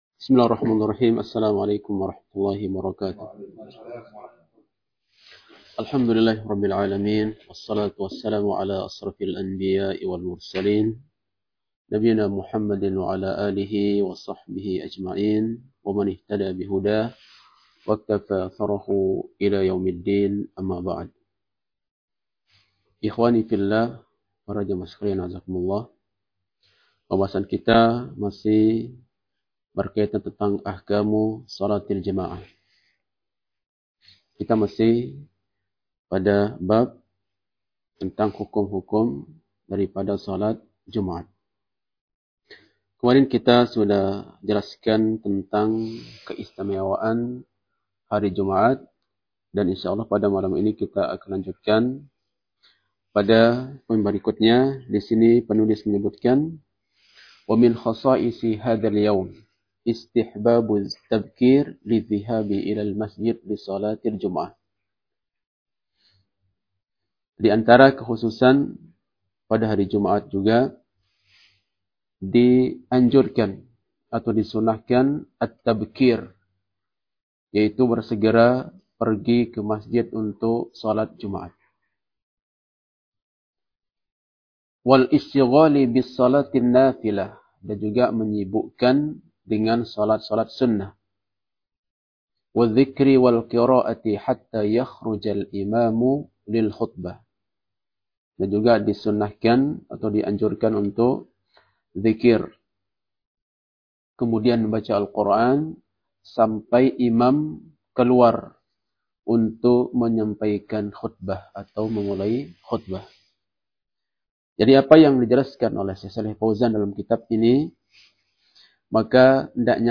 Kajian Ahad – Doha Membahas